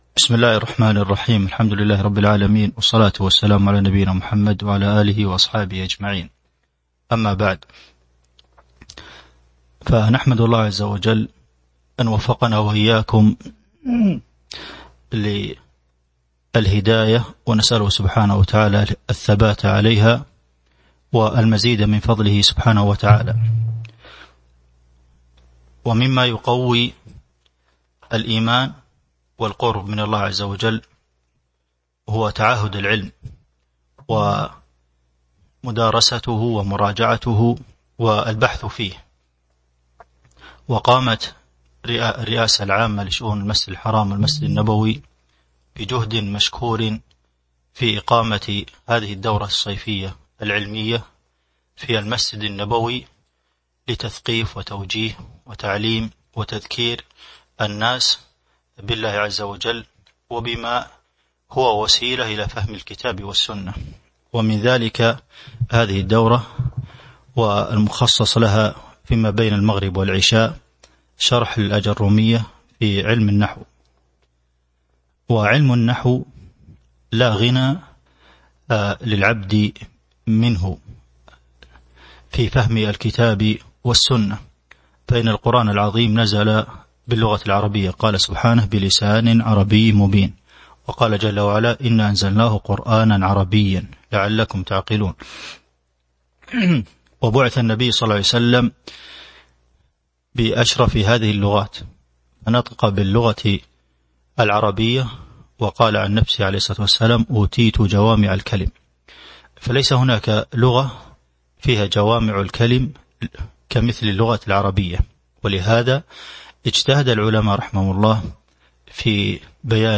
تاريخ النشر ٣ ذو القعدة ١٤٤٢ هـ المكان: المسجد النبوي الشيخ: فضيلة الشيخ د. عبدالمحسن بن محمد القاسم فضيلة الشيخ د. عبدالمحسن بن محمد القاسم المقدمة (01)